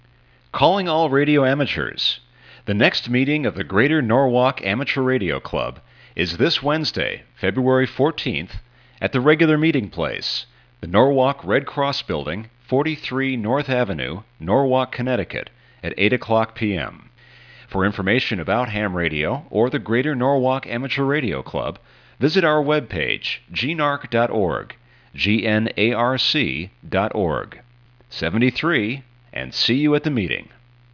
Sample Announcement
This is a sample of a club meeting announcement for EchoStation.